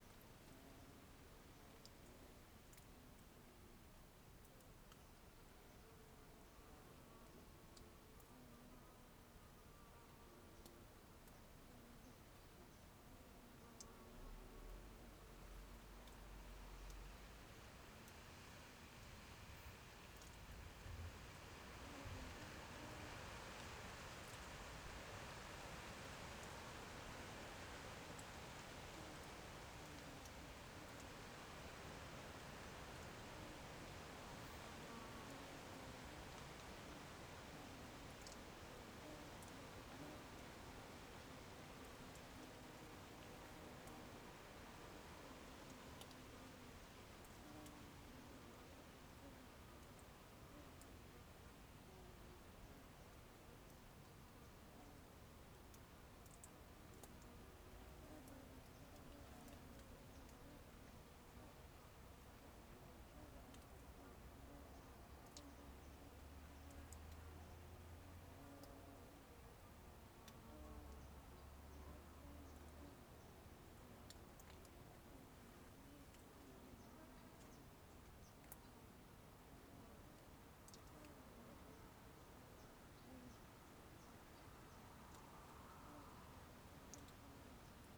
CSC-04-185-LE - Ambiencia viveiro vazio ao meio dia com moscas e vento nas arvores do lado de fora.wav